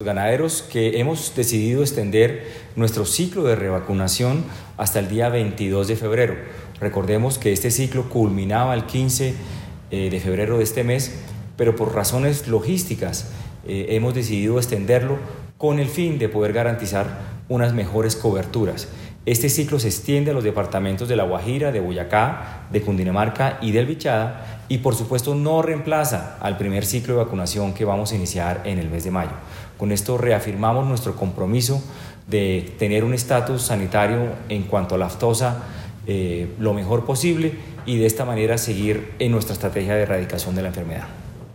Declaraciones sobre el ciclo de revacunación 2019
declaraciones-ministro-agricultura-ciclo-revacunacion-2019.wav